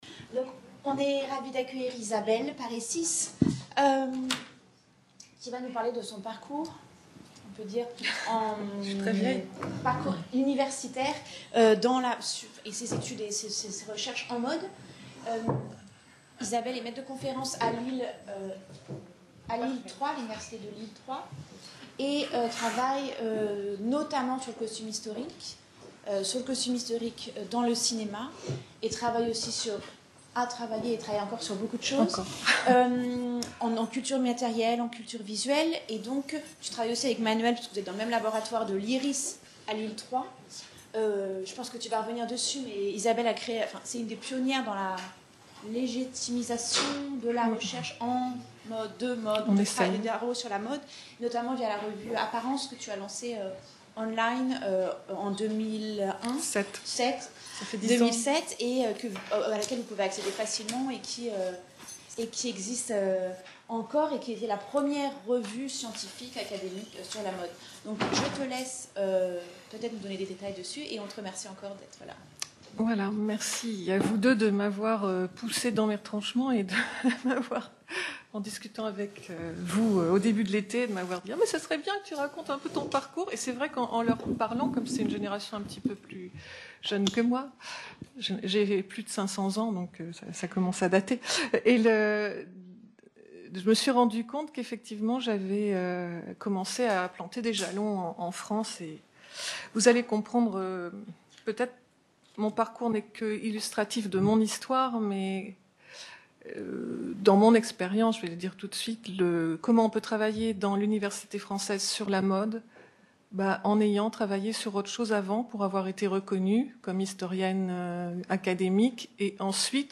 SEMINAIRE DU 29 SEPTEMBRE 2017 / FRENCH FASHION STUDIES